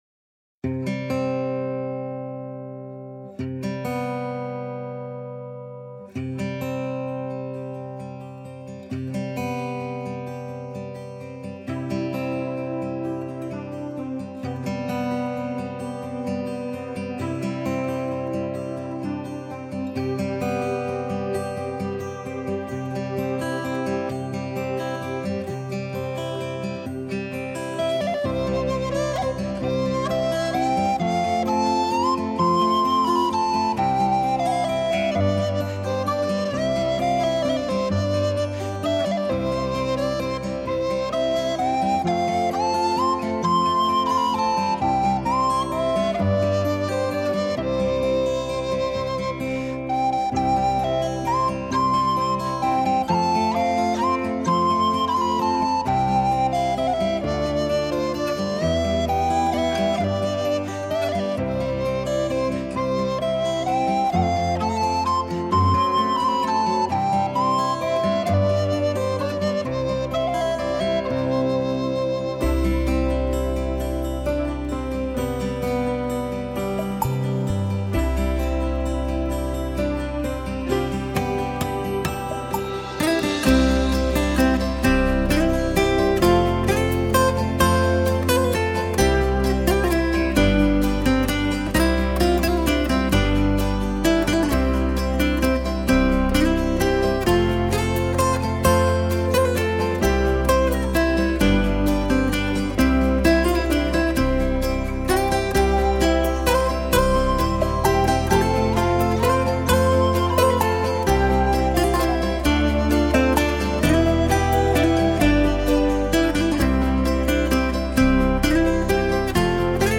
新世纪
★来自瑞士尘不染的音符 空灵飘渺的音乐世界
19世纪的爱尔兰民谣